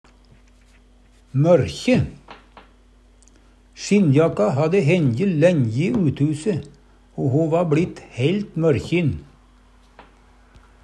mørkjin - Numedalsmål (en-US)